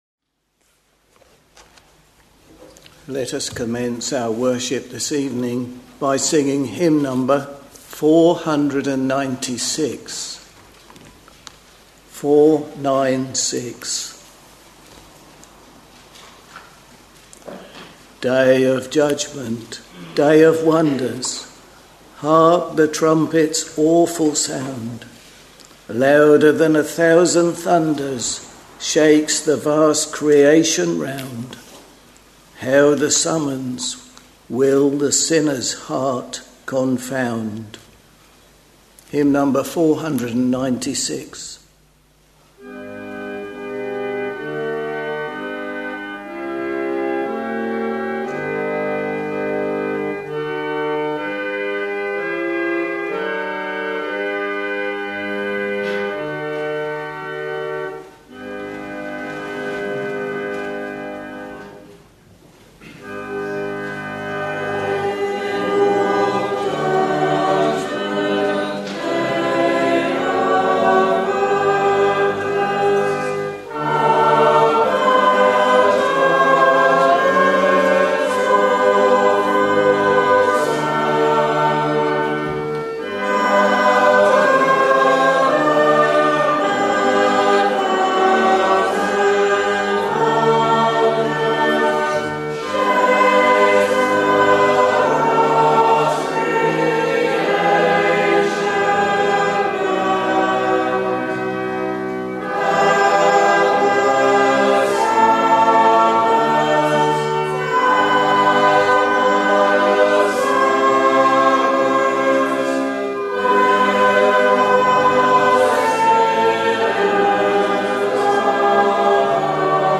Sunday, 28th July 2024 — Evening Service Preacher